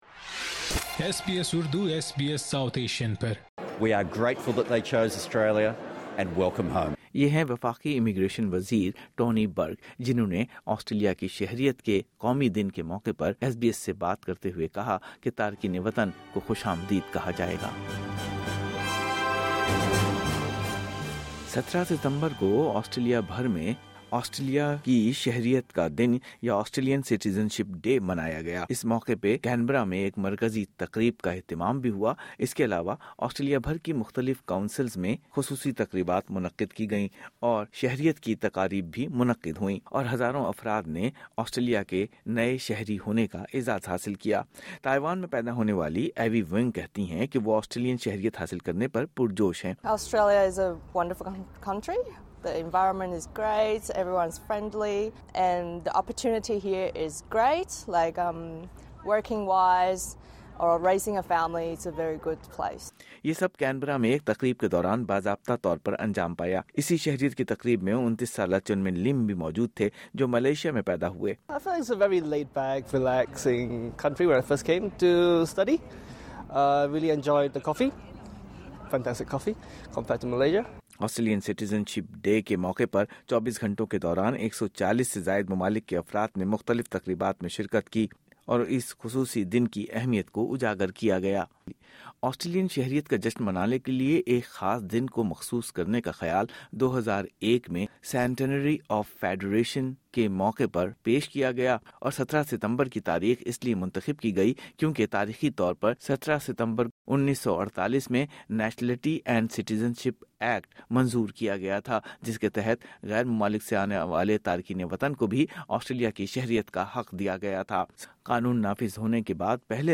ایس بی ایس نے کچھ نئے شہریوں سے بات کی۔